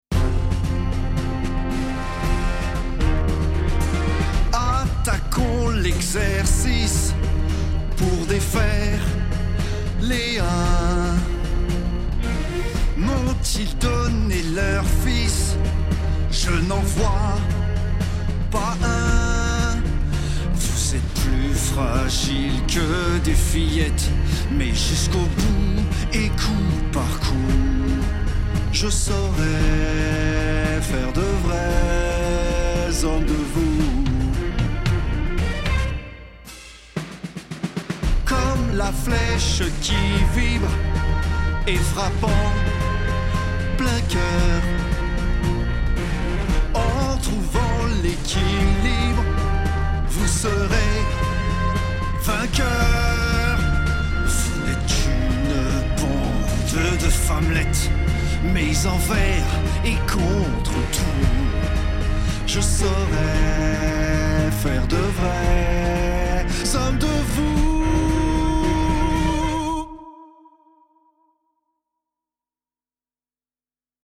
Démo chant